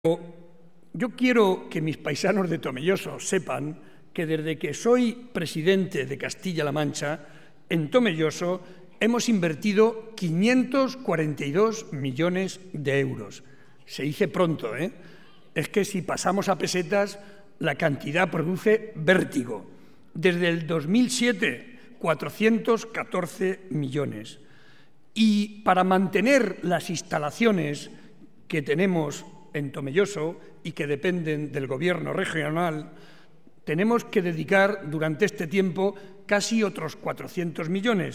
Ante más de 1.500 vecinos de la localidad, en el Teatro Municipal, subrayó que Tomelloso se ha convertido en un nudo de comunicaciones en el corazón de La Mancha.